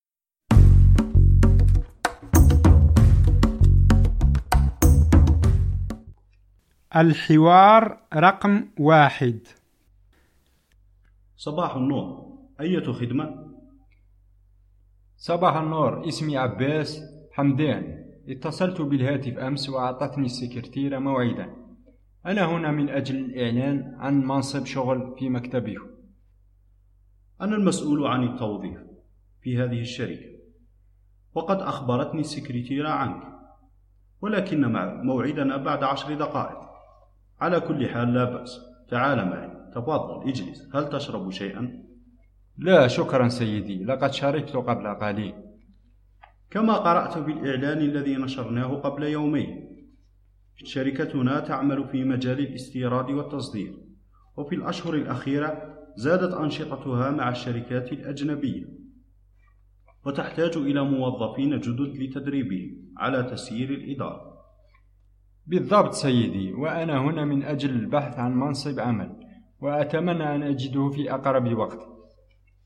فهم المسموع